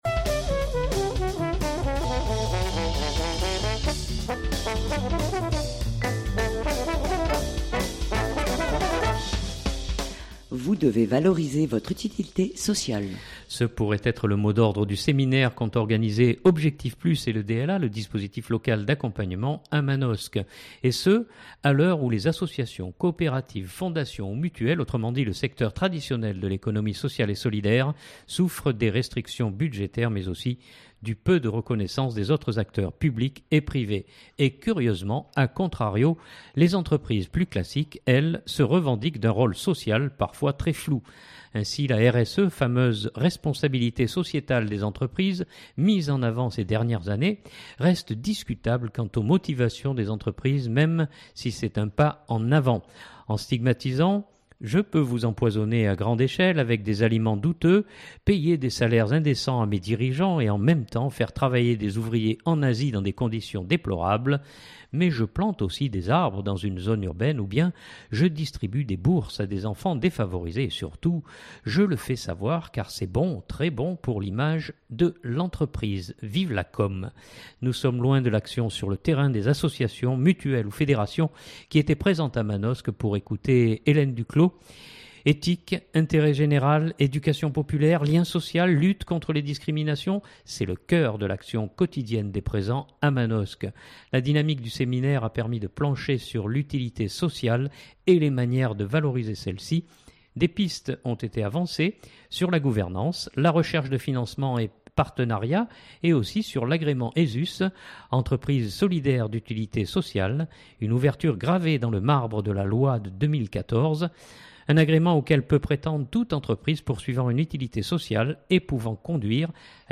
Ce pourrait être le mot d’ordre du séminaire qu’ont organisé Objectif Plus et le DLA (dispositif local d’accompagnement) à Manosque.